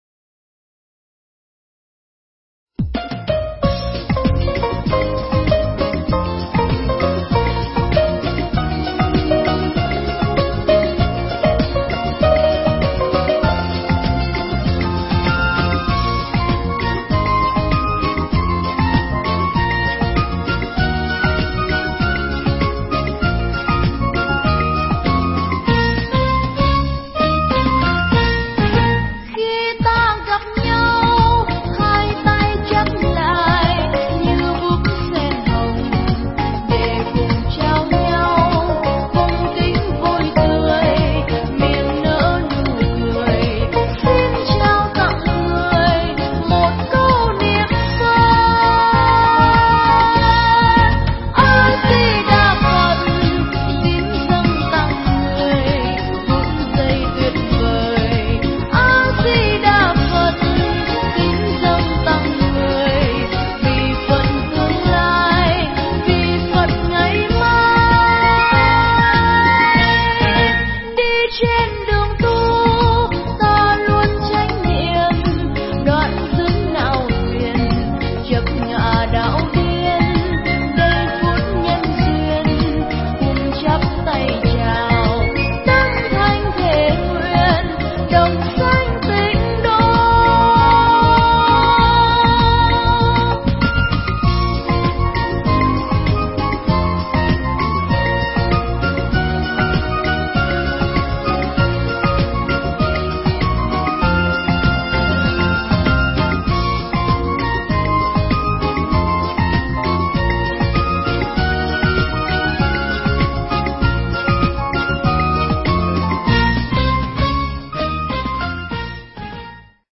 Mp3 Thuyết pháp Tạ Đàn Dược Sư